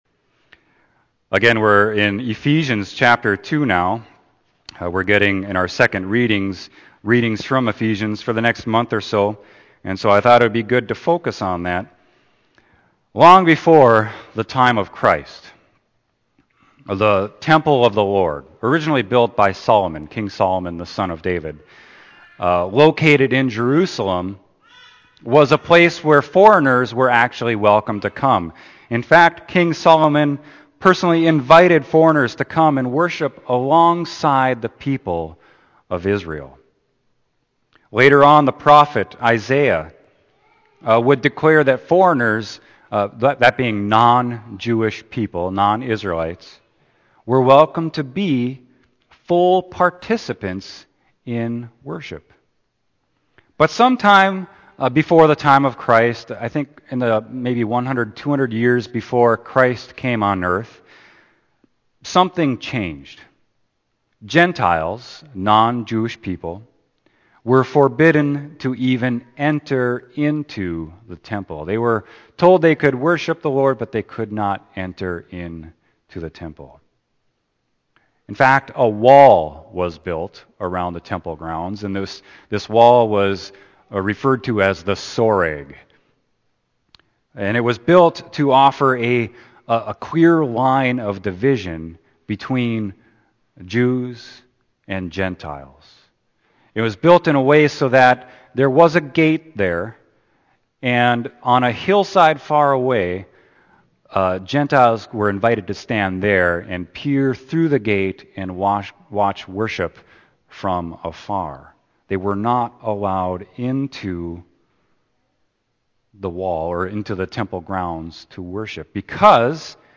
Sermon: Ephesians 2.11-22